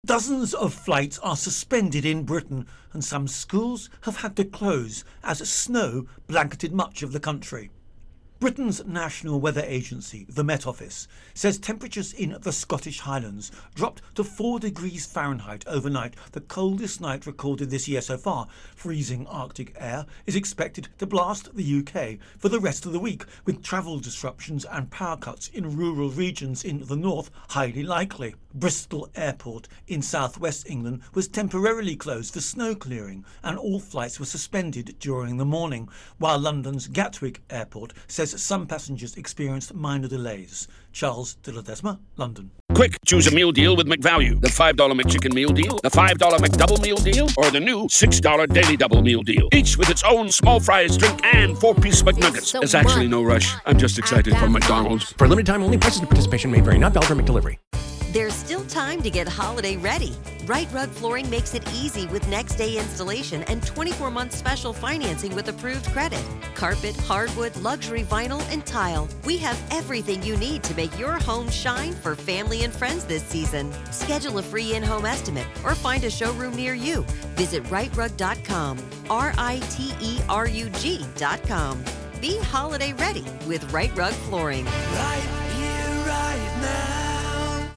reports on Europe-Weather